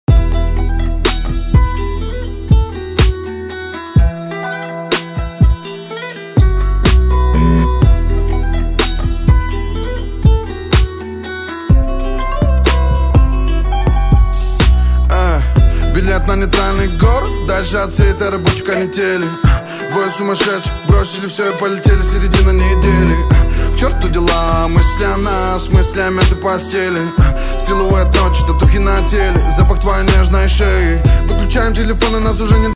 • Качество: 160, Stereo
лирика
Хип-хоп
чувственные